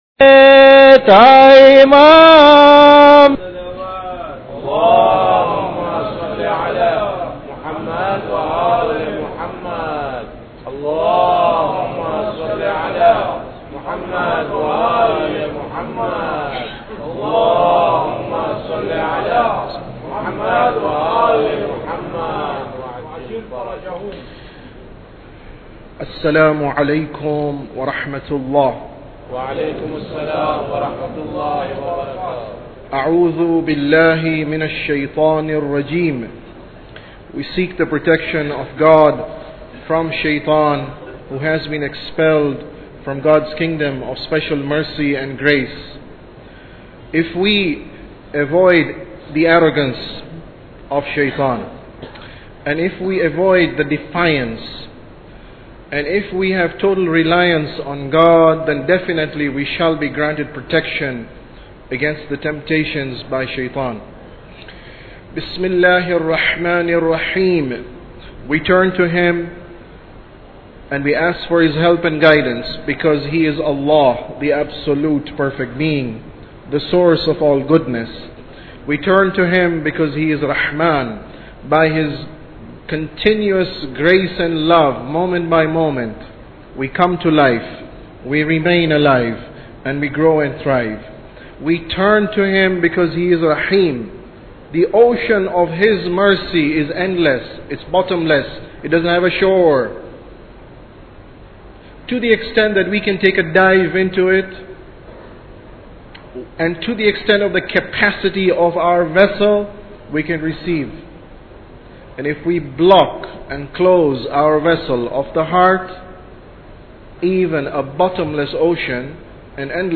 Sermon About Tawheed 7